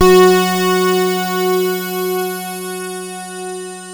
KORG F#4 1.wav